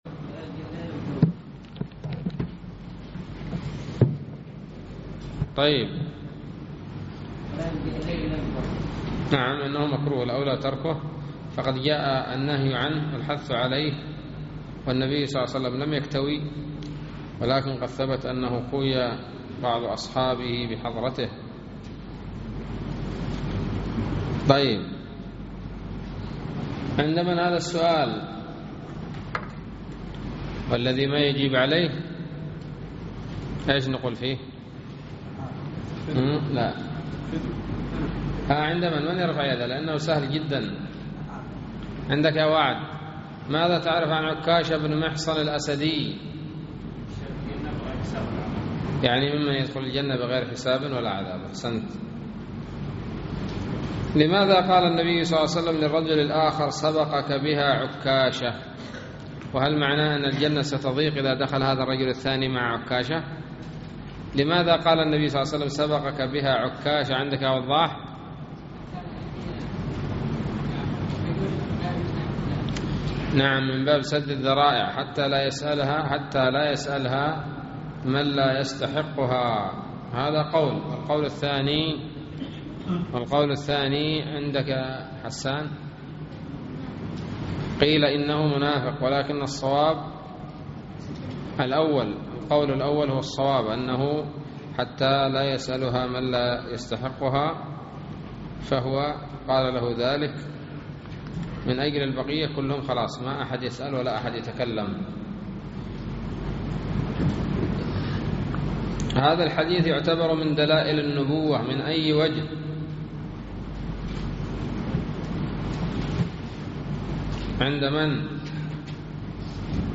الدرس الحادي عشر من شرح كتاب التوحيد